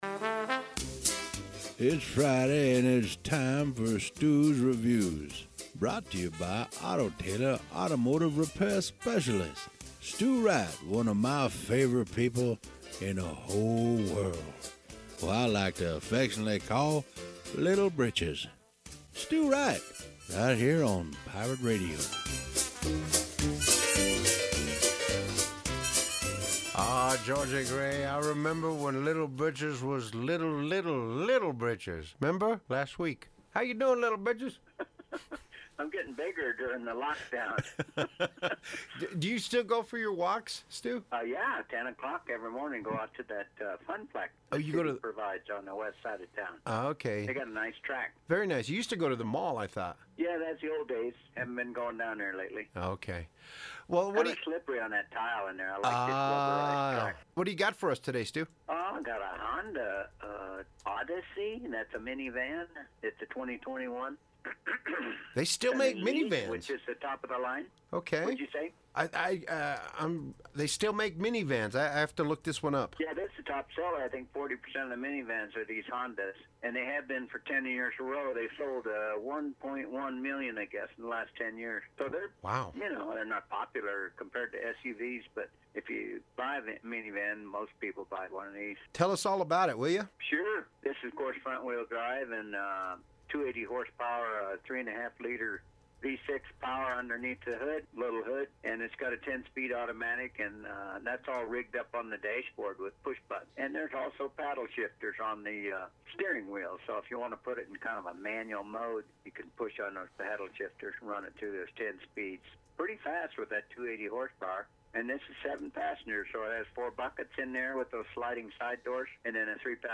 Radio review